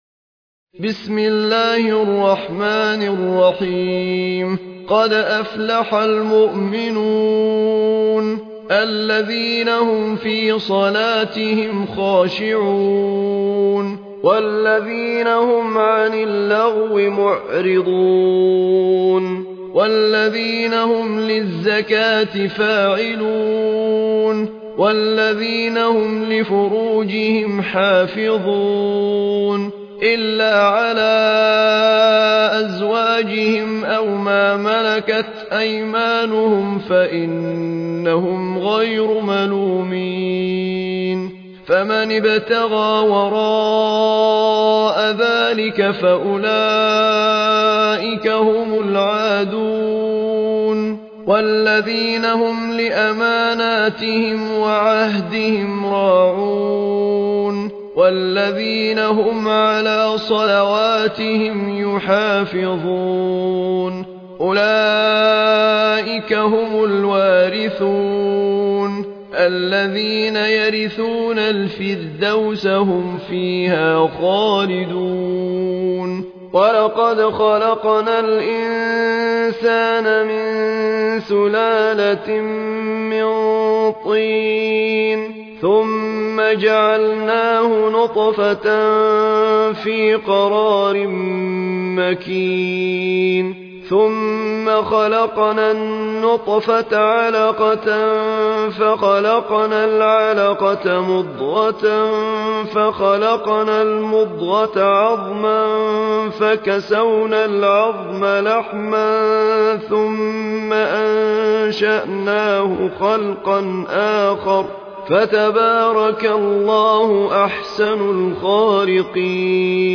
المصحف المرتل - ذكوان عن ابن عامر - المؤمنون